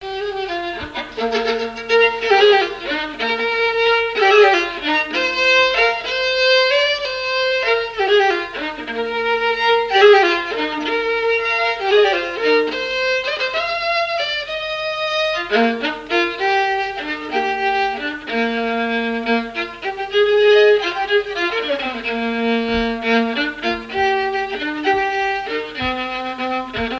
Mémoires et Patrimoines vivants - RaddO est une base de données d'archives iconographiques et sonores.
danse : valse
Le quadrille et danses de salons au violon
Pièce musicale inédite